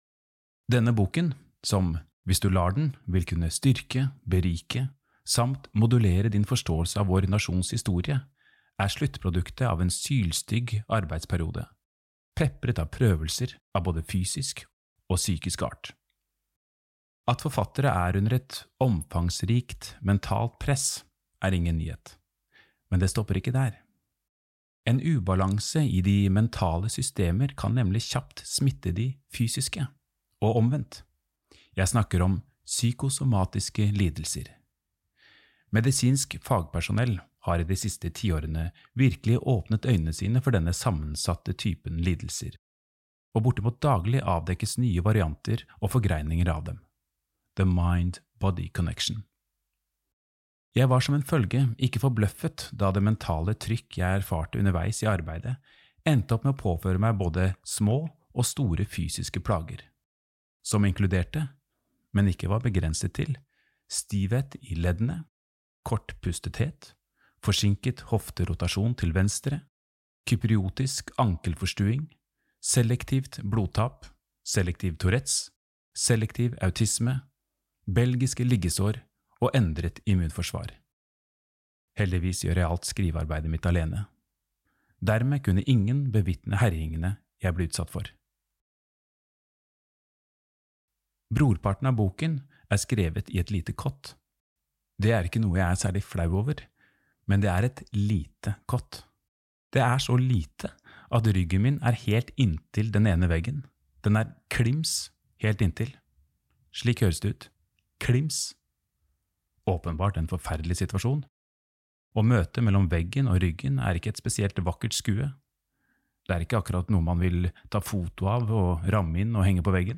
Nedlastbar lydbok
Innlest av - passende nok - hele Norges Nicolai Cleve Broch.